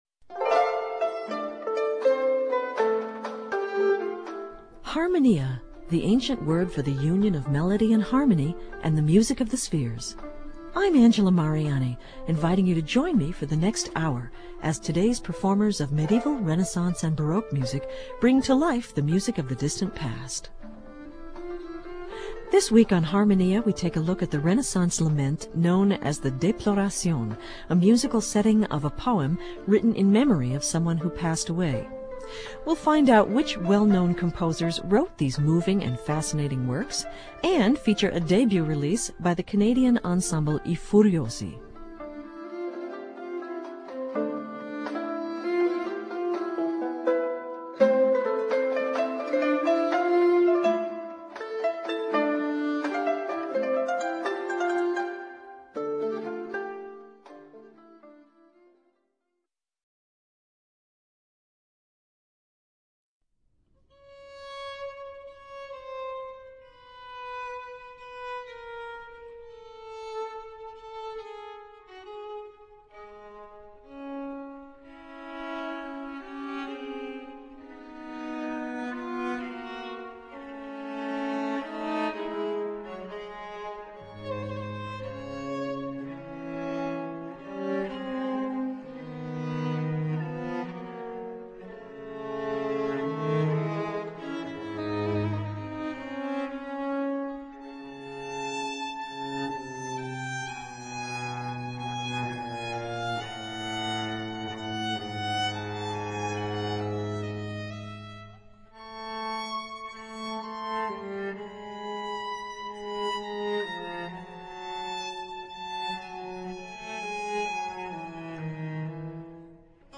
Here's a video of I Furiosi performing Andrea Falconiero's "Folias": The music heard on this episode was performed by Ensemble P.A.N. , The Clerks' Group , Piffaro , The Hilliard Ensemble , and I Fagiolini .